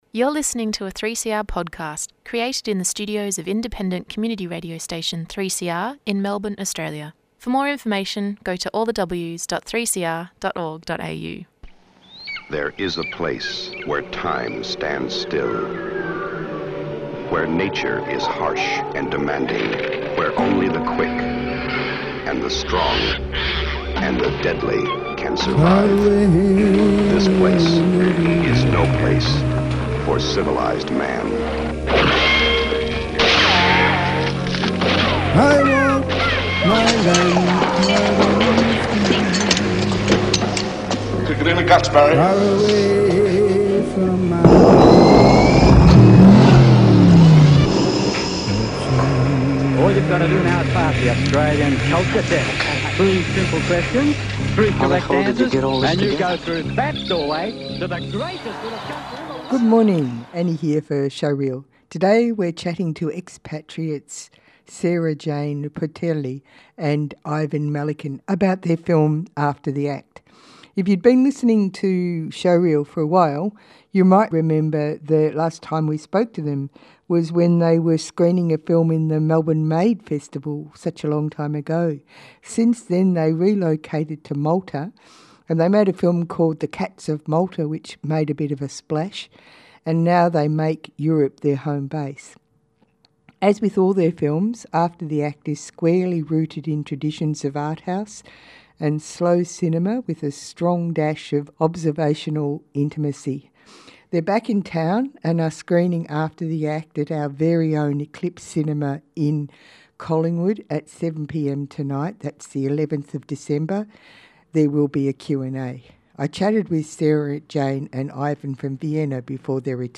There will be a Q&A. Tweet Showreel Thursday 11:00am to 11:30am Your half hour of local film news, conversations with film makers and explorations into how they bring their ideas to life on screen.